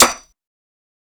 TC2 Snare 17.wav